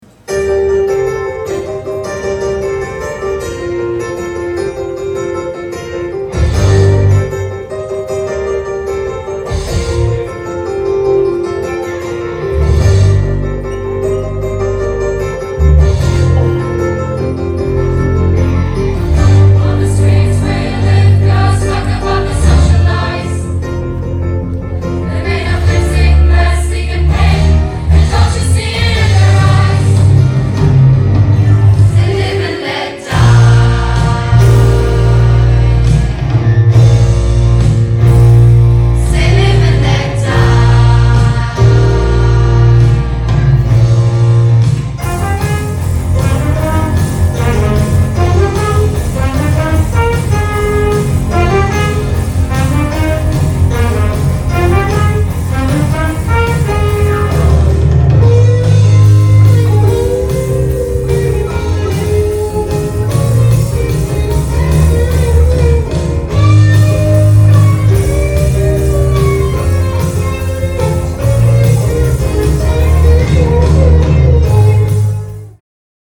um in der Probenarbeit einen homogenen Bandsound zu kreieren, der sich stets am Originalsound orientiert.
Die variierende Besetzung aus Rockband (Schlagzeug, Bass, E-Gitarre) und Bläser/Streicher bietet ein reiches Repertoire, das neben Pop-/Rockmusik auch Filmmusik und Musicalsongs beinhaltet.